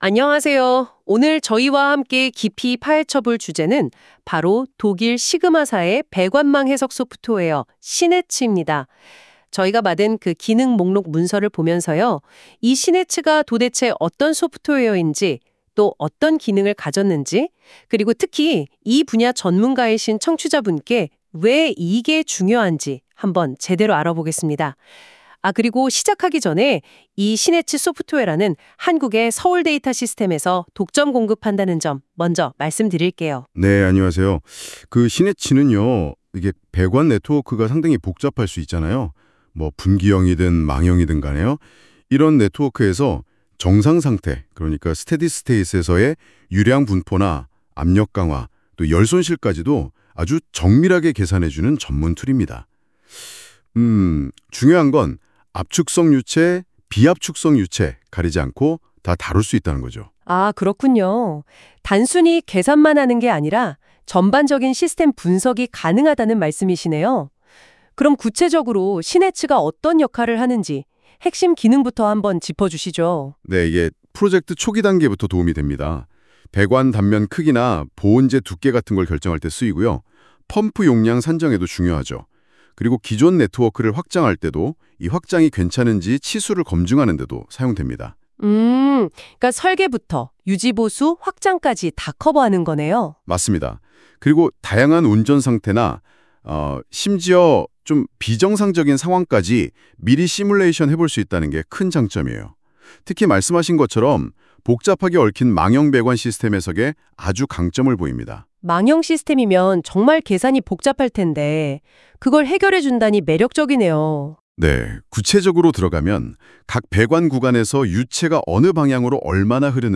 SIGMA의 소프트웨어를 AI 기반의 팟캐스트 형식으로 들어보세요.
각 제품의 핵심 개념과 차별점까지, 인공지능의 목소리로 안내해드립니다.